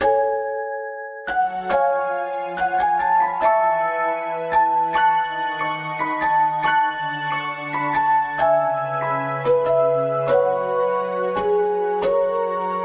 が…、これはデフォでこの音質